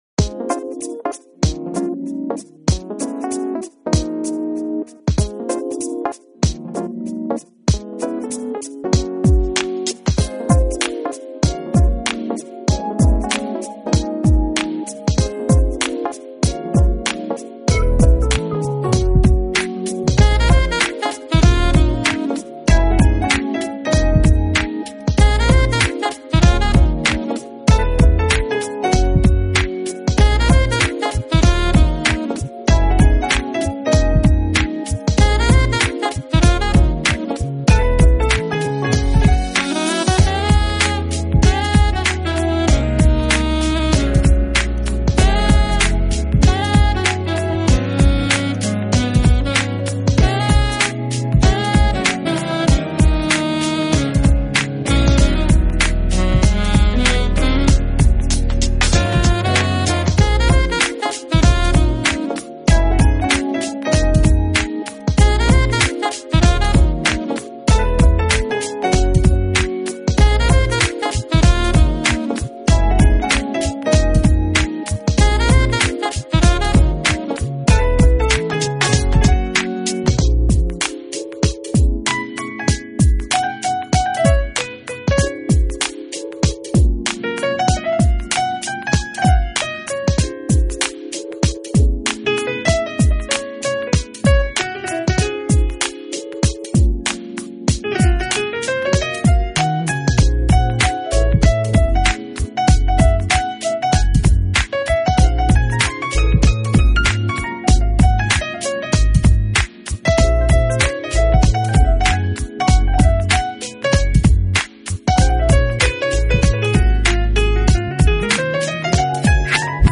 动听的音符在你耳边轻轻地滑过，享受着音乐所带来的一切，选择音乐亦在选择心情 ，